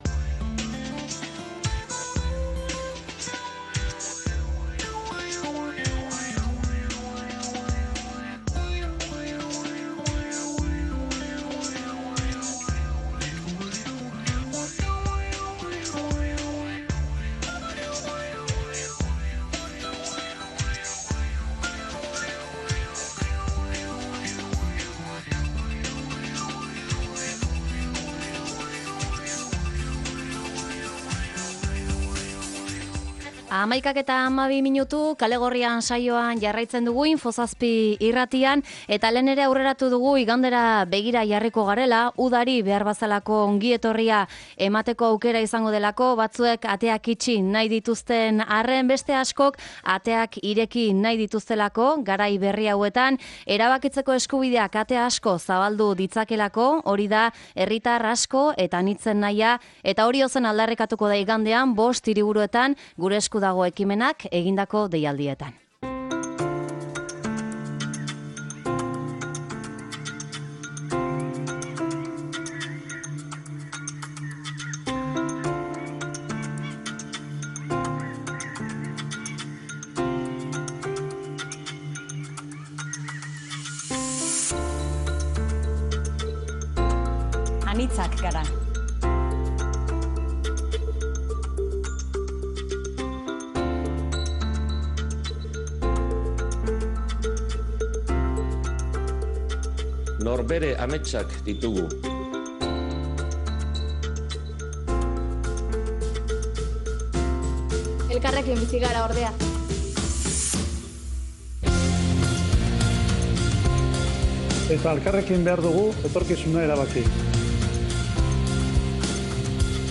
Ondoren sortu zen Gure Esku Dago ekimenari eta joan den urteko giza kateari berriz, “Jostunak” dokumentalak eman dio forma. Ez dakigu igandekoaren ondoren trilogia etorriko ote den baina guk orain arteko ikus-entzunezkoetan ibili diren hiru lagun elkartu ditugu mahaiaren bueltan